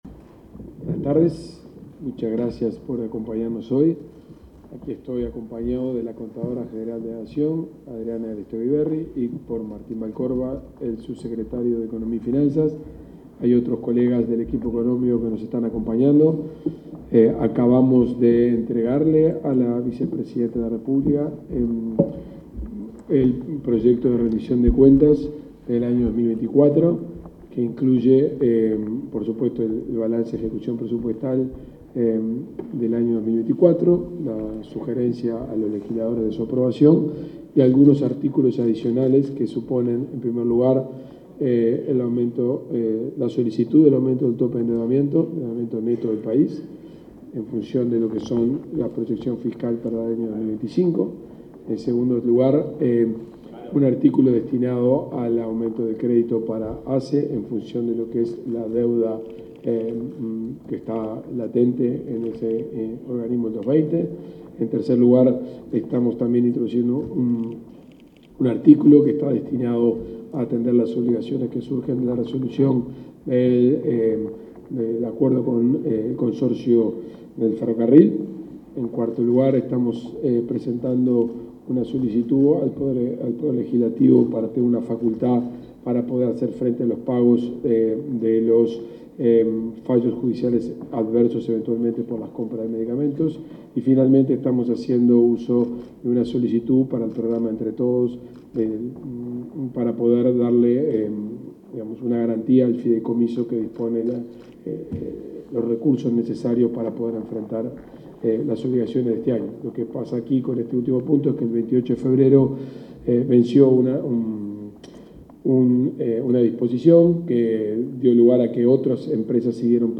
Palabras del ministro de Economía, Gabriel Oddone 02/06/2025 Compartir Facebook X Copiar enlace WhatsApp LinkedIn El titular de Economía y Finanzas, Gabriel Oddone, se expresó en una conferencia de prensa tras la presentación del proyecto de Rendición de Cuentas y Balance de Ejecución Presupuestal de 2024 en el Palacio Legislativo.